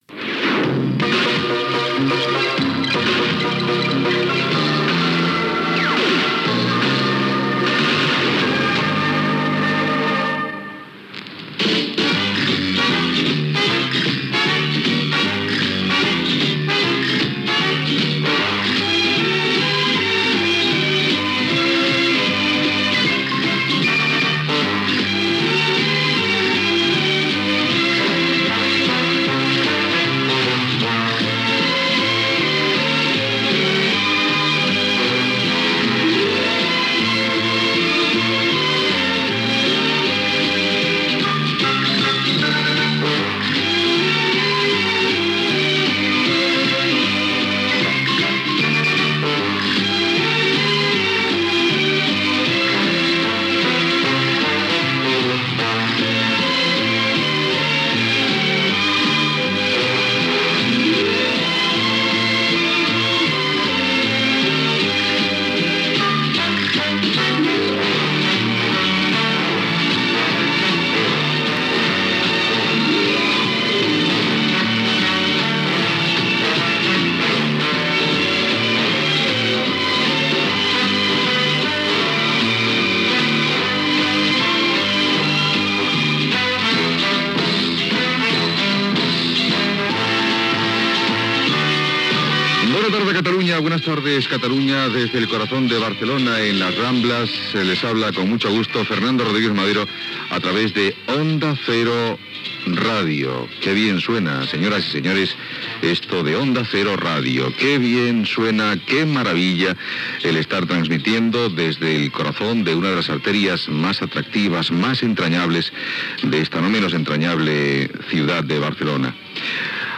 Sintonia, benvinguda a la primera edició de Protagonistas Catalunya, hora, salutació de Xavier Casas, regidor president del districte de Ciutat Vella de Barcelona, freqüències d'emissió i tema musical
Info-entreteniment